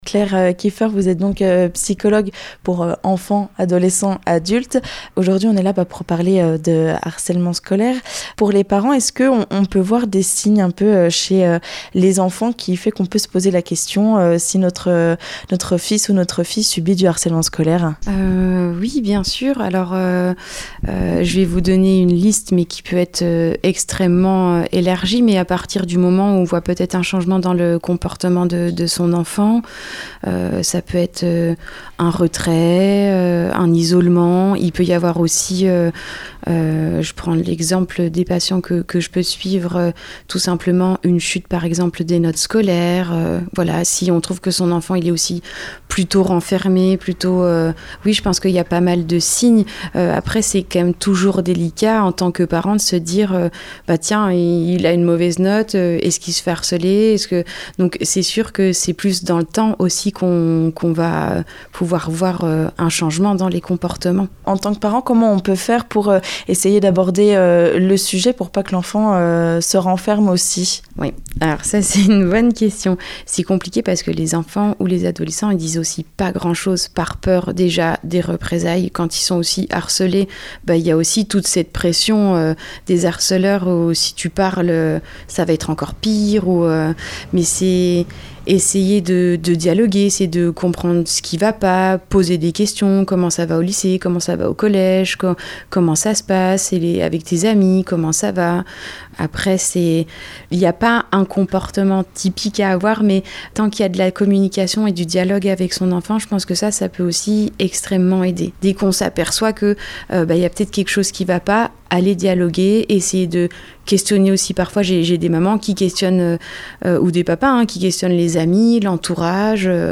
Harcèlement scolaire : une psychologue nous livre les clés pour aborder le sujet avec ses enfants